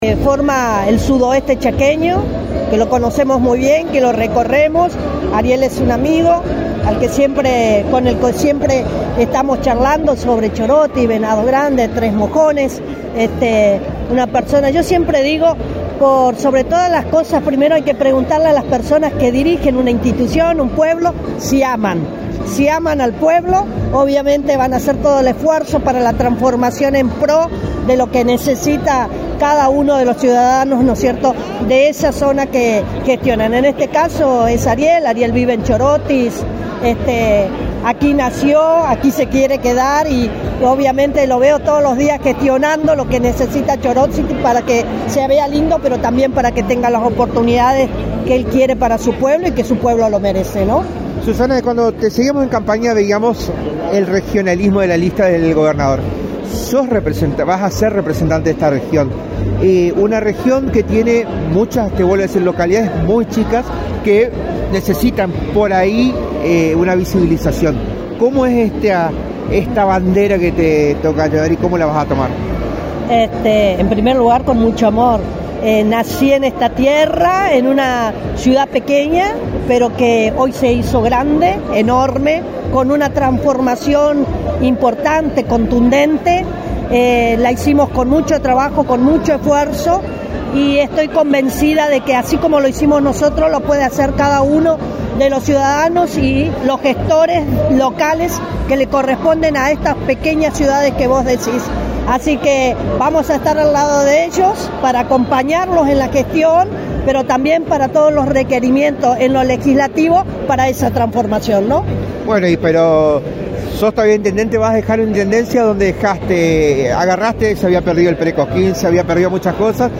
En una jornada de emociones profundas y actos concretos de gobierno, Susana Maggio, una de las referentes políticas más reconocidas del sudoeste chaqueño, acompañó la entrega de viviendas y el aniversario de la localidad de Chorotis, donde no ocultó su orgullo y emoción por los logros alcanzados en su gestión y por el nuevo desafío que comienza como diputada provincial.
Con la voz entrecortada, Maggio cerró con un mensaje potente:
El cierre fue con un aplauso cálido de los presentes y un mensaje de gratitud a los vecinos: “Gracias por acompañarme, por empujar conmigo. Este camino recién empieza”.